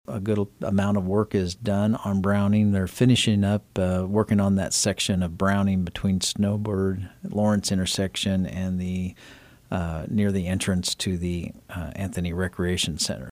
Fehr says most of the construction is completed on Browning at this time.